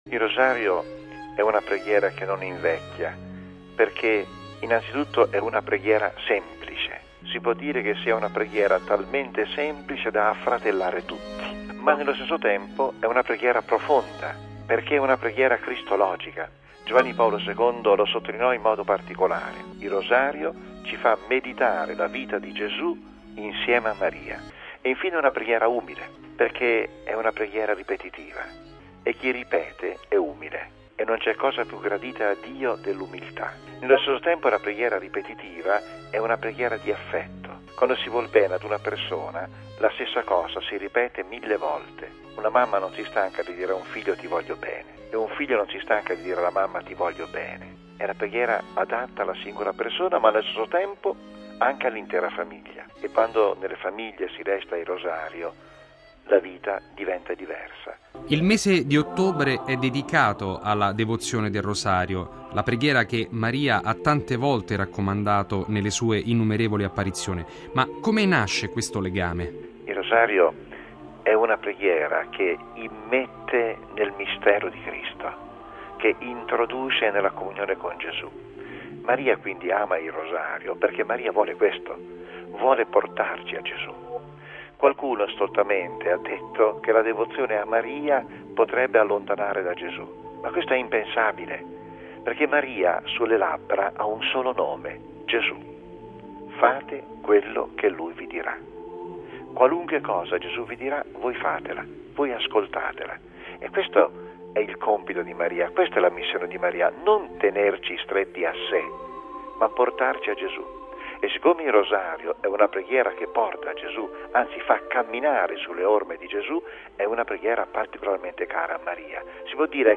Ma su questa antica e sempre nuova preghiera ascoltiamo la riflessione dell’arcivescovo Angelo Comastri, vicario del Papa per lo Stato della Città del Vaticano.